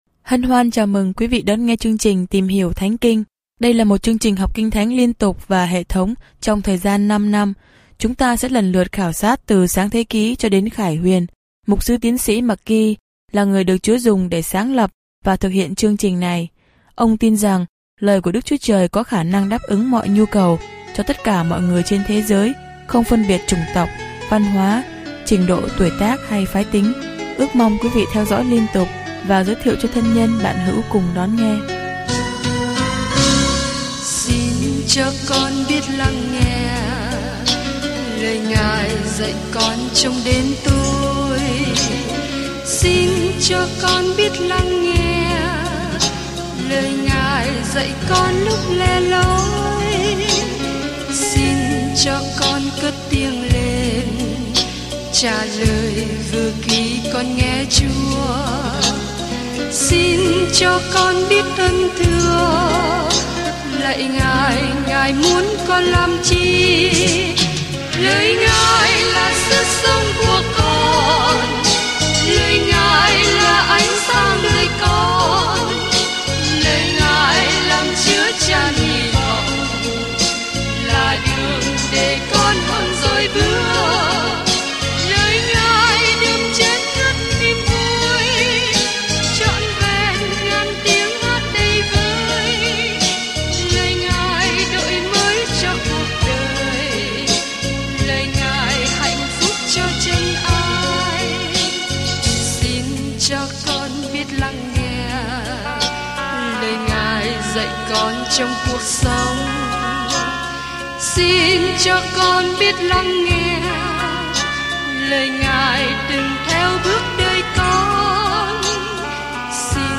Kinh Thánh Phục Truyền 25 Phục Truyền 26 Ngày 15 Bắt đầu Kế hoạch này Ngày 17 Thông tin về Kế hoạch Sách Đệ Nhị Luật tóm tắt luật tốt lành của Thiên Chúa và dạy rằng sự vâng phục là sự đáp trả của chúng ta đối với tình yêu của Ngài. Du lịch hàng ngày qua Phục truyền luật lệ ký khi bạn nghe nghiên cứu âm thanh và đọc những câu chọn lọc từ lời Chúa.